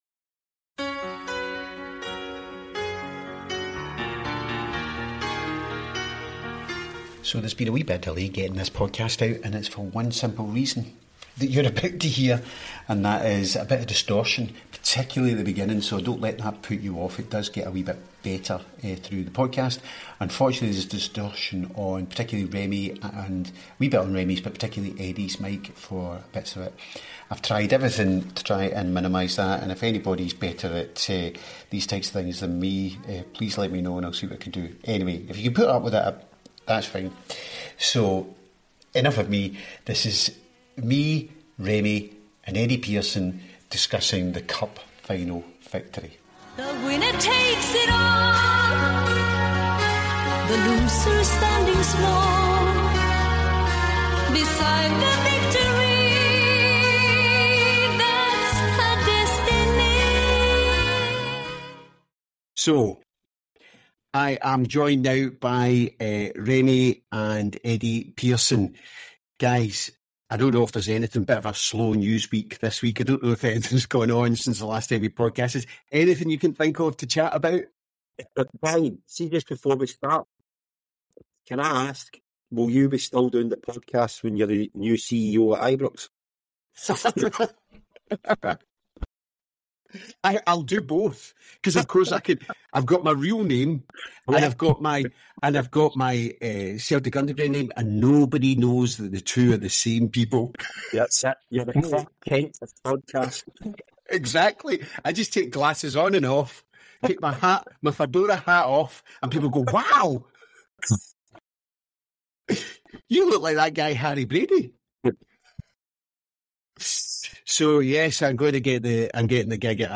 The worst of it appears to be at the beginning and the end.